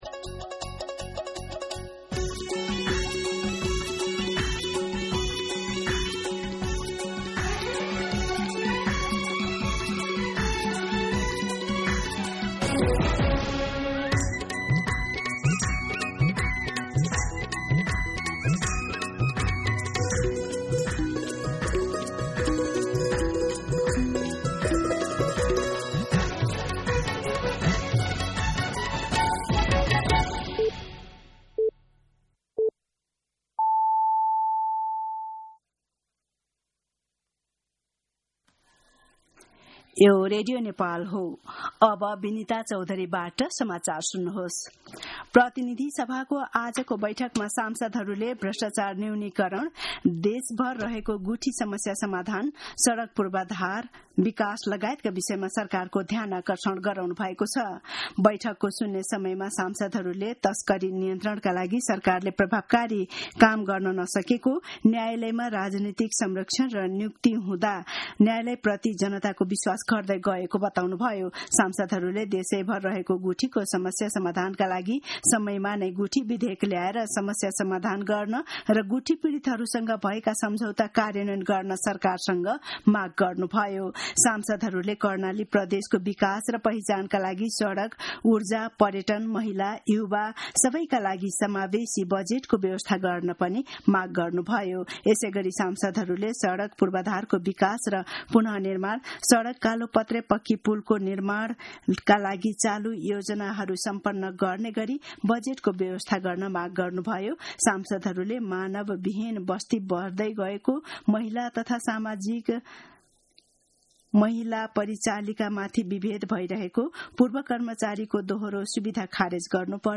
दिउँसो १ बजेको नेपाली समाचार : ५ जेठ , २०८२